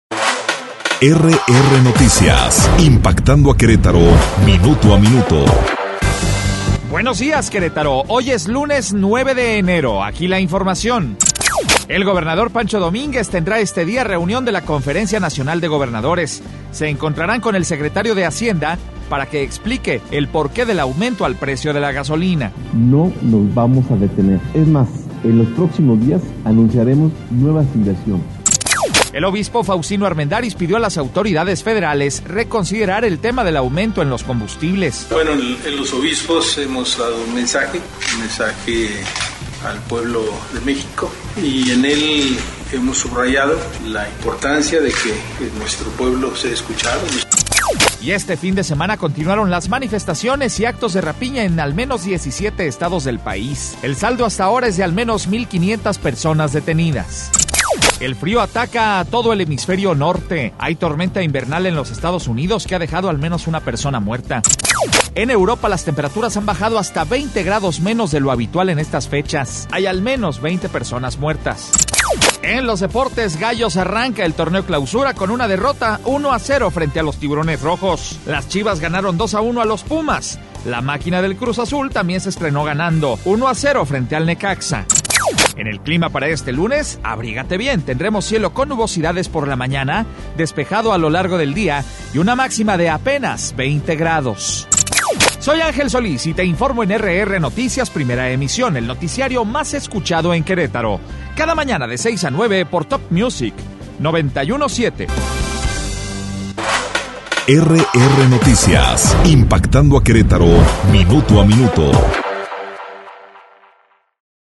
Resumen Informativo 9 de enero - RR Noticias